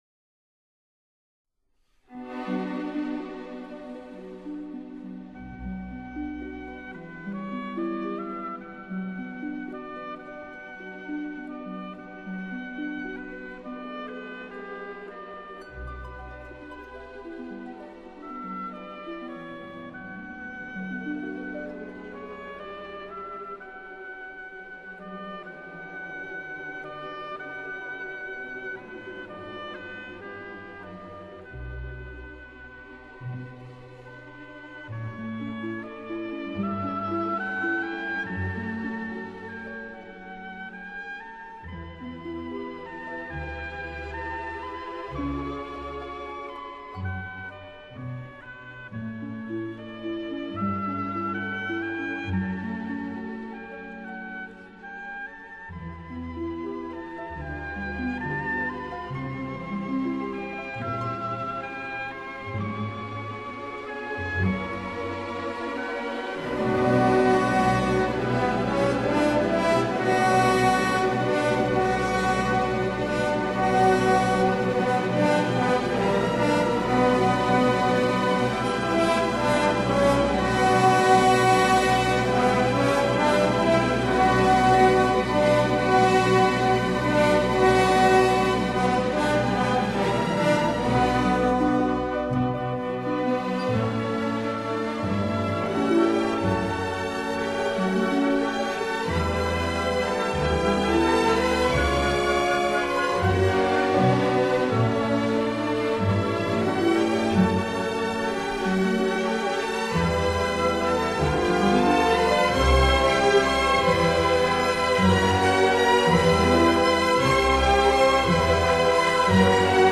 Suite de ballet op. 20